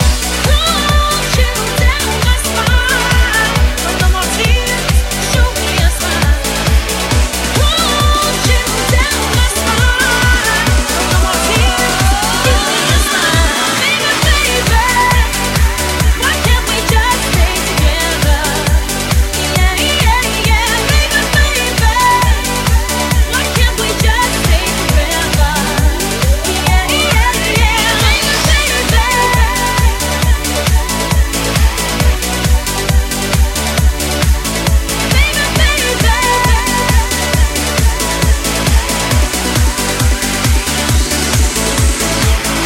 hits remixed
Genere: club, dance, edm, electro, house, successi, remix